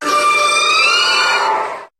Cri de Momartik dans Pokémon HOME.